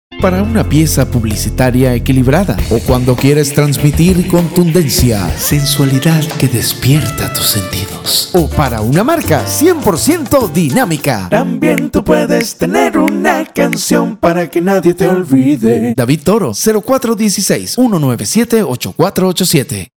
Locutor neutro profesional, voz juvenil
spanisch Südamerika
Sprechprobe: Werbung (Muttersprache):
Neutral and young voice